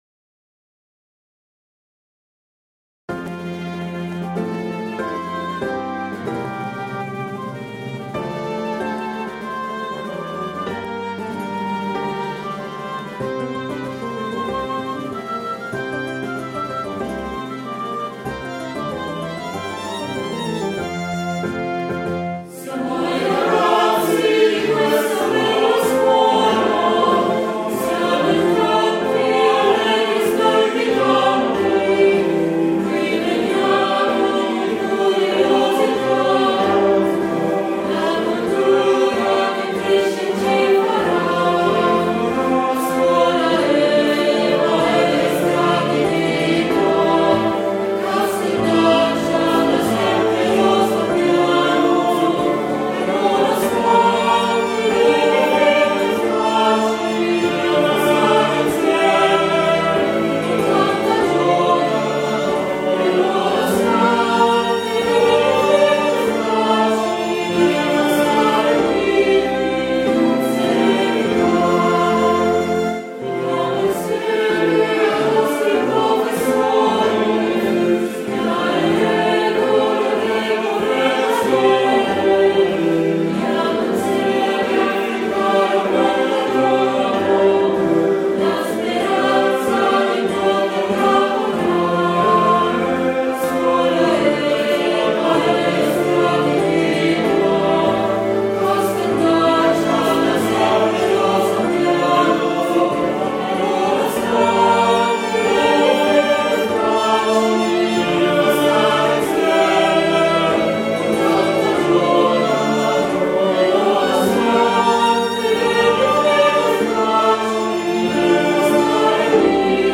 Inno-della-scuola-di-Casteldaccia-con-voce.mp3